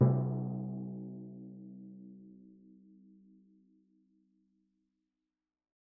Timpani3B_hit_v3_rr1_main.mp3